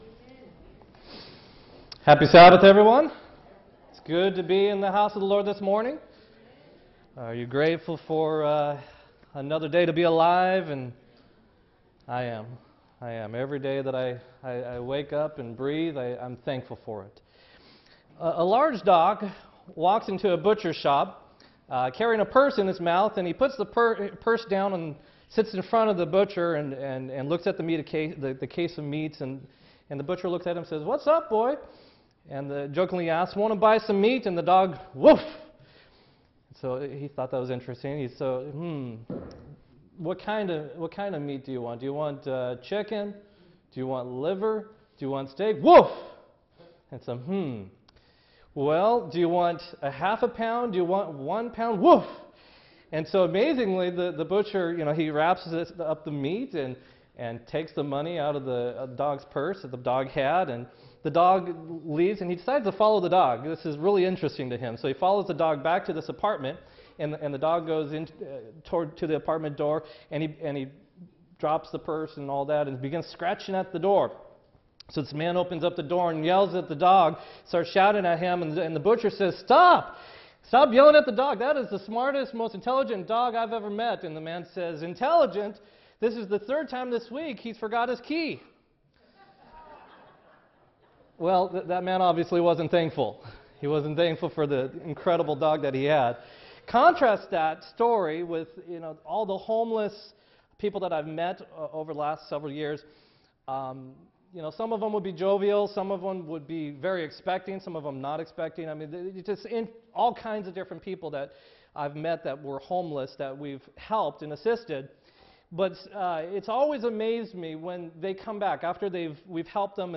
11-5-16-sermon